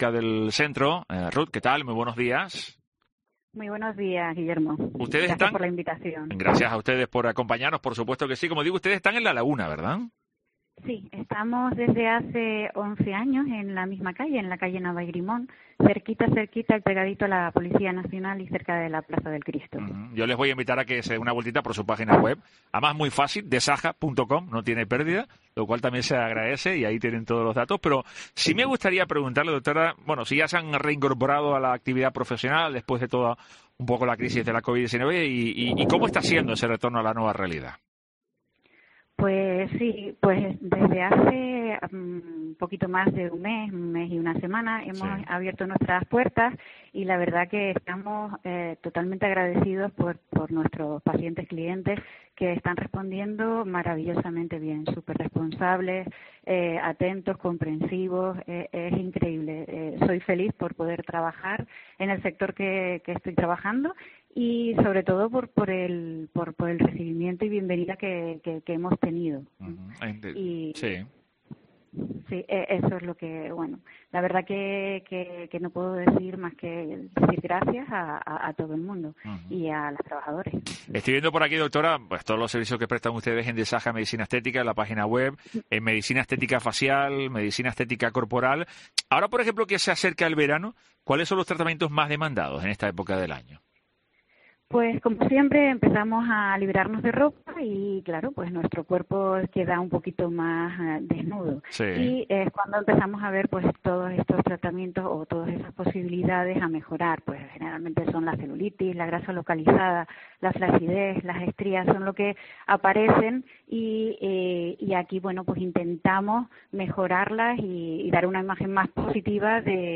Nuestra interlocutora, nos ha contado hoy en La Mañana de Cope Tenerife que “desde hace un mes y una semana hemos vuelto a abrir nuestras puertas tras el confinamiento y estamos muy agradecidos a nuestro clientes por el recibimiento que hemos tenido.